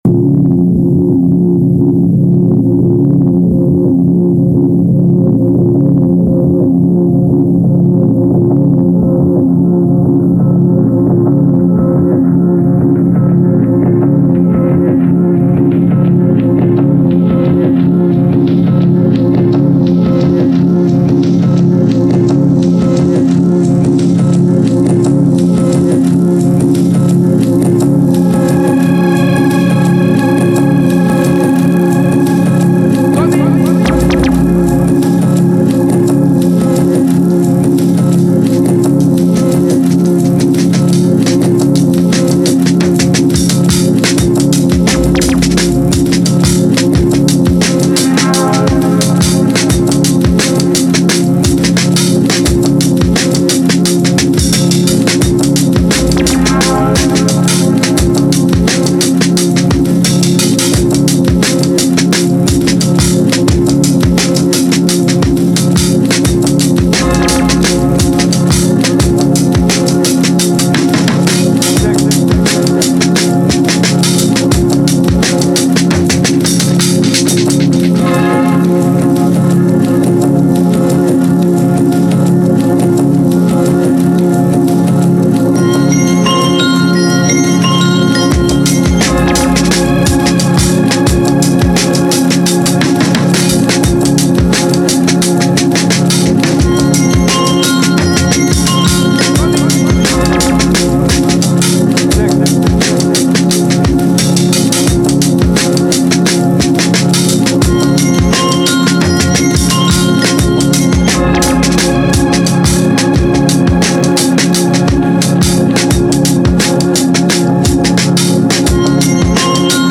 Some very recognisable samples from elsewhere in this but the main melodies and bass are from the Hibiscus tune.
Drums are super crisp too, and you can take all the time you need if it helps pump out bangers like this :beers:
Didn’t know if I was going to be able to do anything with the sample but it turned out to have really nice textures once stemrolled and messed with.